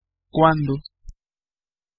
Ääntäminen
IPA : /ˈwɛn/